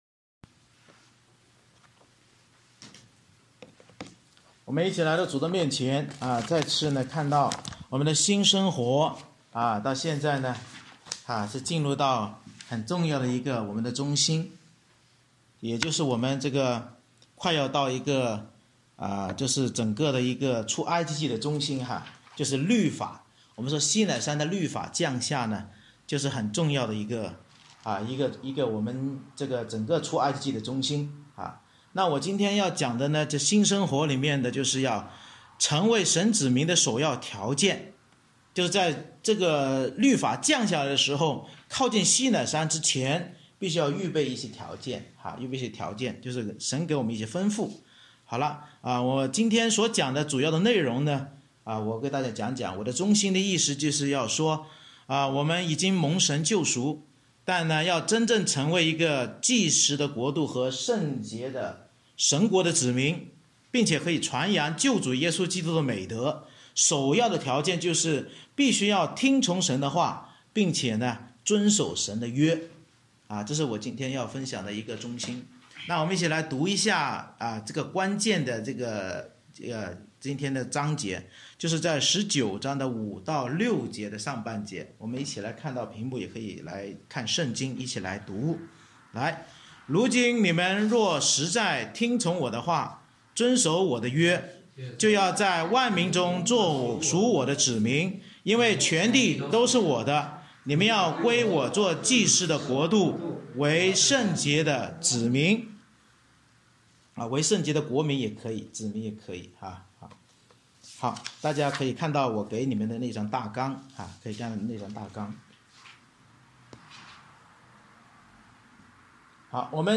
出埃及记19章 Service Type: 主日崇拜 Bible Text